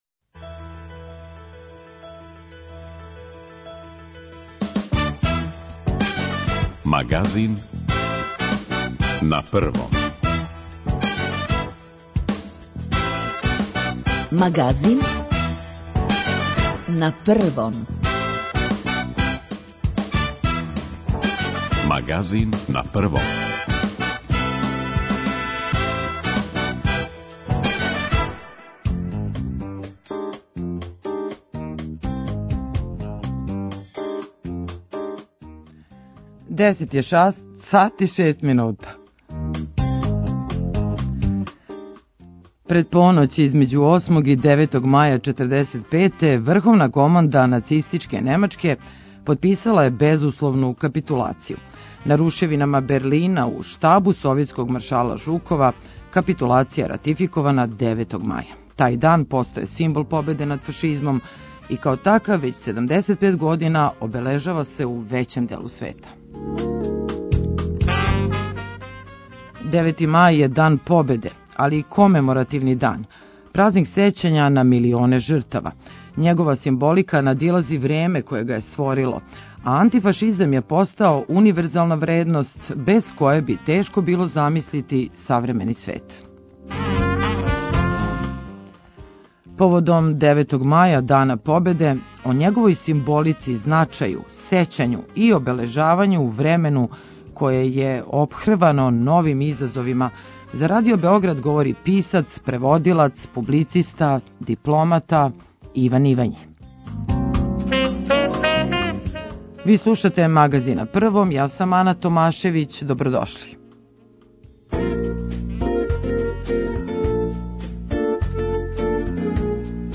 Поводом 9. маја, Дана победе, о његовој симболици, значају, сећању и обележавању у времену које је опхрвано новим изазовима за Радио Београд говори писац, публициста и дипломата Иван Ивањи.